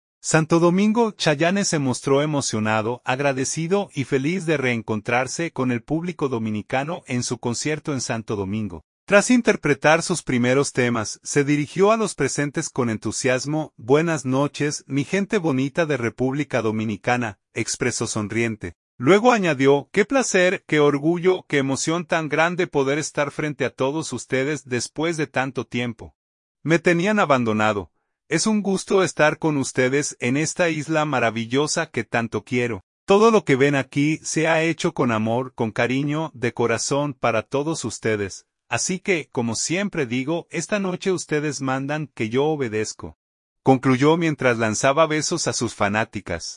Santo Domingo.- Chayanne se mostró emocionado, agradecido y feliz de reencontrarse con el público dominicano en su concierto en Santo Domingo.
Tras interpretar sus primeros temas, se dirigió a los presentes con entusiasmo: “¡Buenas noches, mi gente bonita de República Dominicana!”, expresó sonriente.